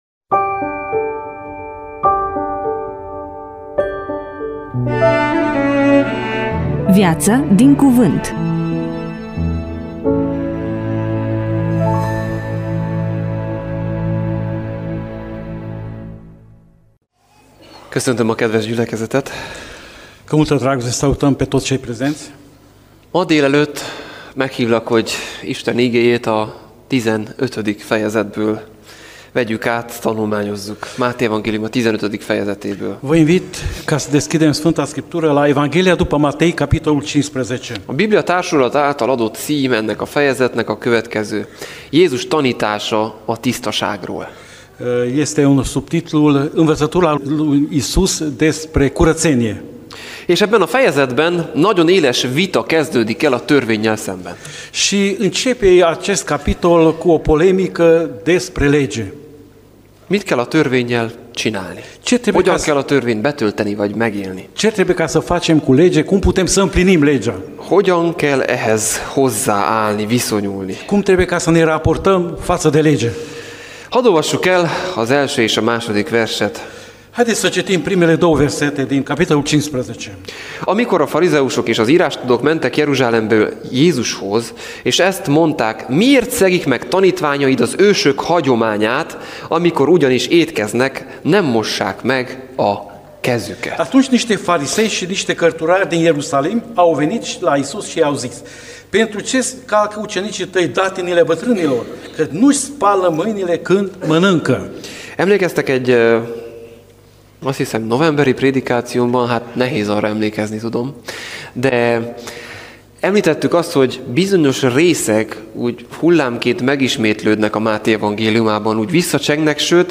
EMISIUNEA: Predică DATA INREGISTRARII: 28.02.2026 VIZUALIZARI: 9